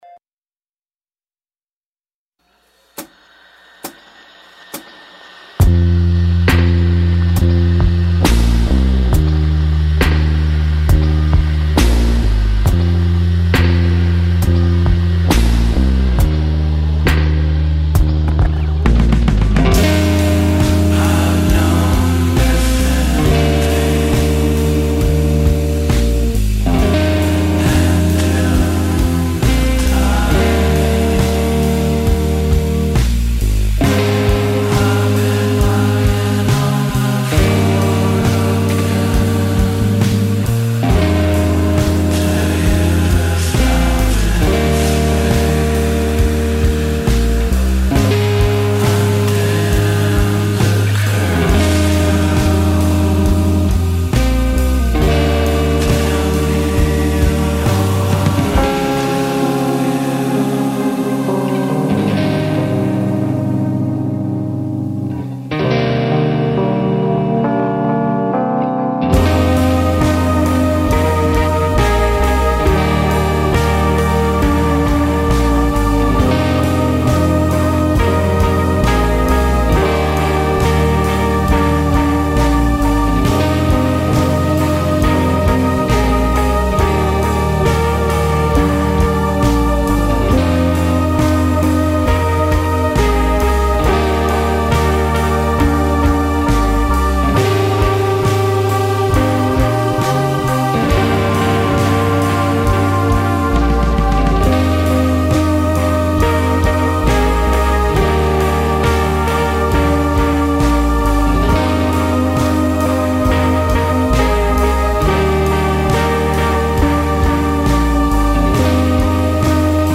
Il sabato del villaggio... una trasmissione totalmente improvvisata ed emozionale.